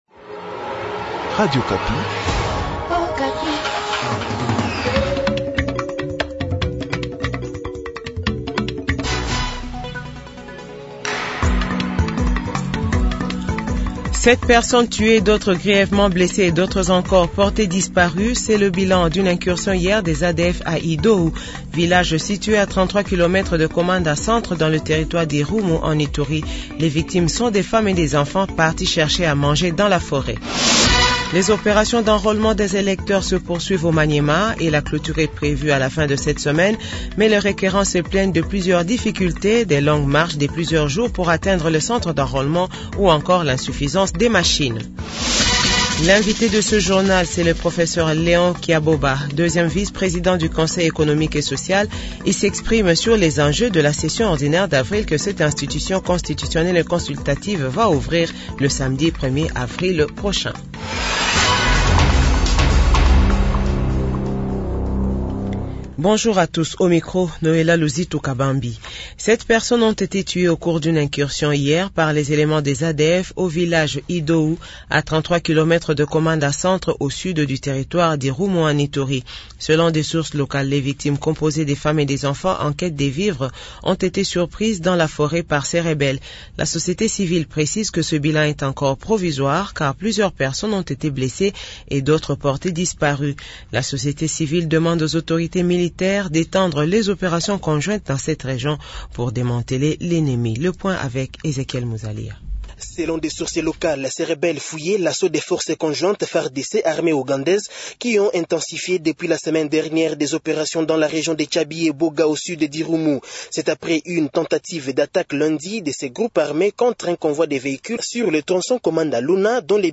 Journal Francais de 15h00